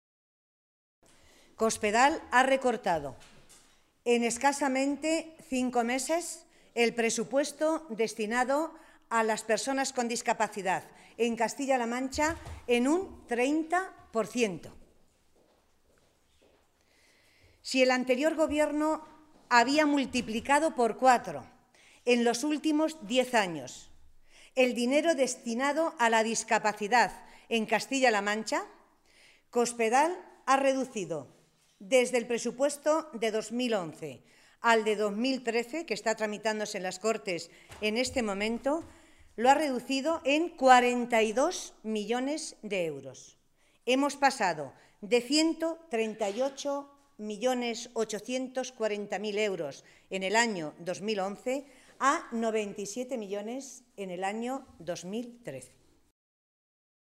Matilde Valentín, portavoz de Asuntos Sociales del Grupo Socialista
Cortes de audio de la rueda de prensa